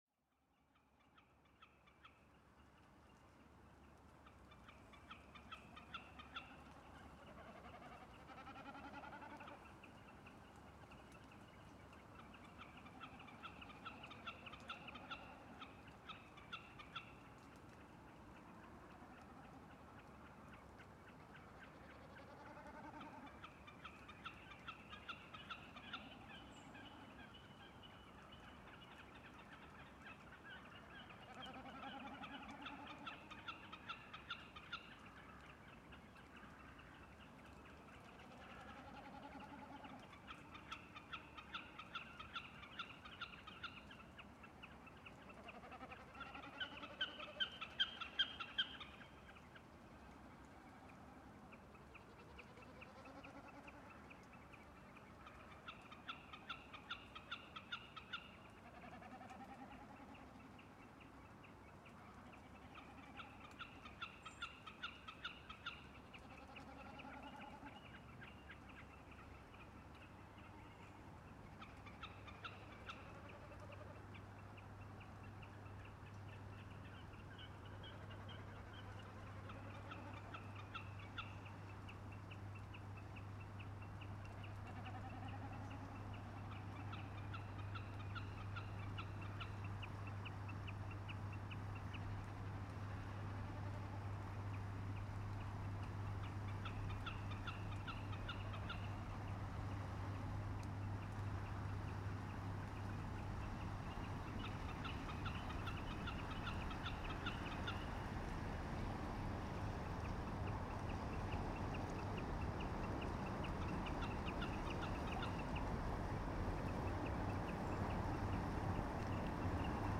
The following recording is a combination of five recordings I made in and around Ljósafoss power station the summer 2013.
The birdsong in the recording is from a nearby county at Laugarvatn which is my grandmothers’ birthplace.